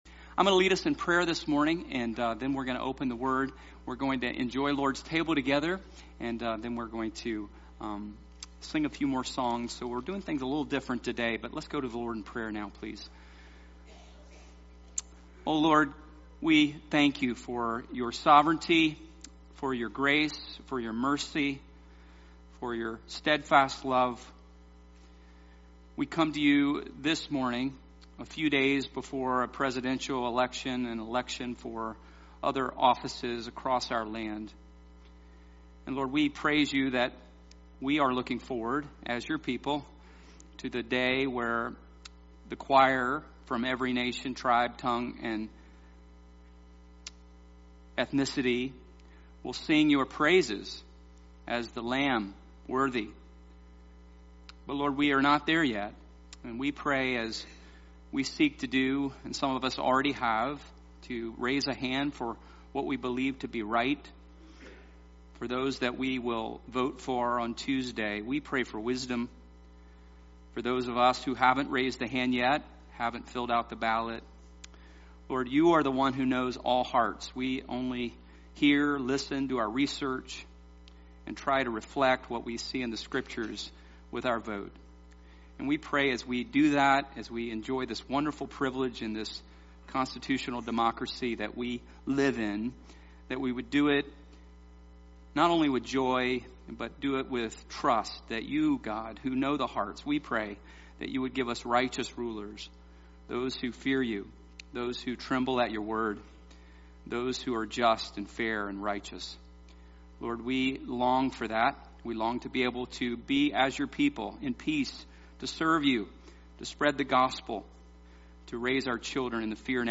Sermon Series on the book of Mark